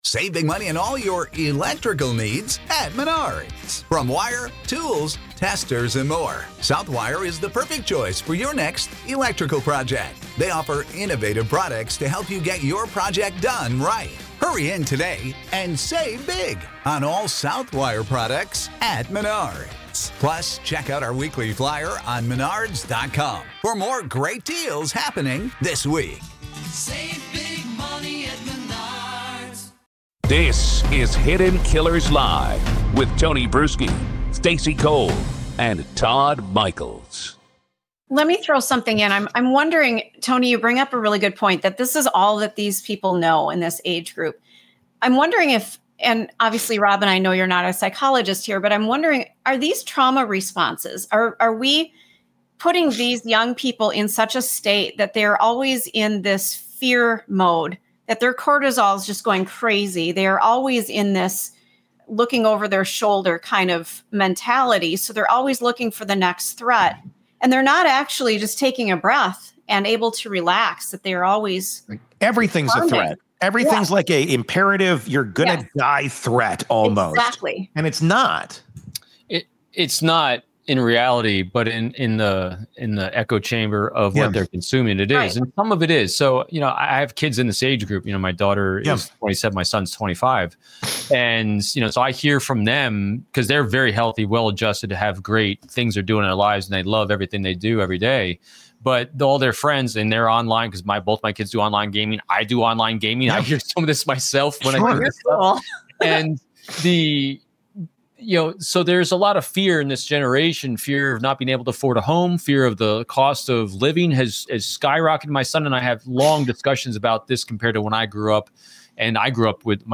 The panel critiques leadership failures on all sides, pointing out how politicians and corporations have abandoned curiosity in favor of division. They stress that leaders must model curiosity and empathy if society is going to calm down.